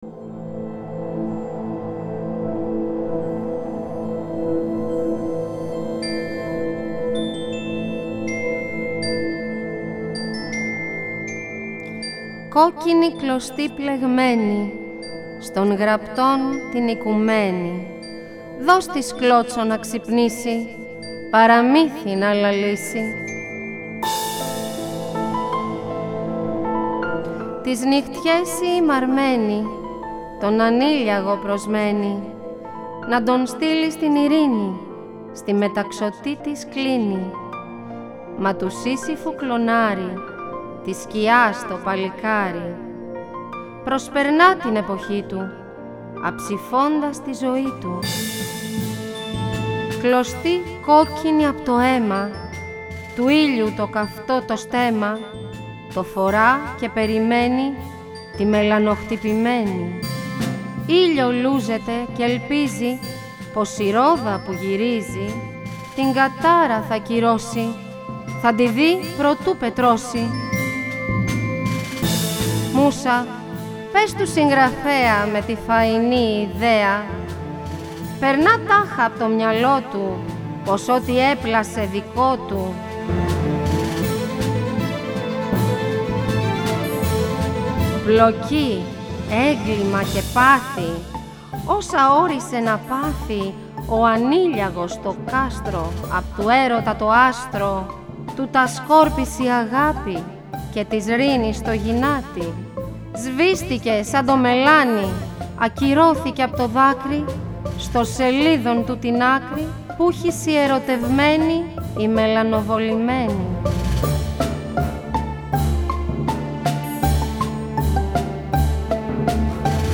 Το μουσικό έργο Μελανοχτυπημένη αποτελείται απο 7 θέματα τα οποία δημιουργήθηκαν ως μουσική υπόκρουση για την απαγγελία των έμμετρων ποιημάτων του ομότιτλου μυθιστορήματος της Αρχοντούλας Αλεξανδροπούλου.
ΑΠΑΓΓΕΛΙΕΣ